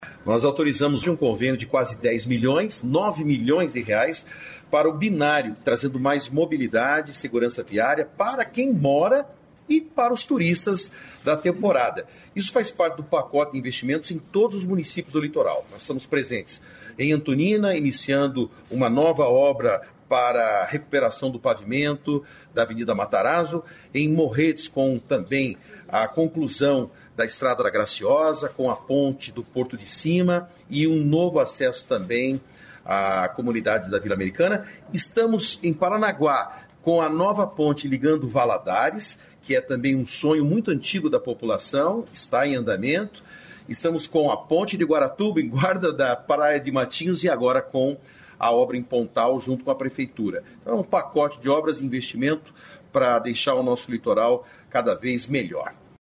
Sonora do secretário de Infraestrutura e Logística, Sandro Alex, sobre o investimento em Pontal do Paraná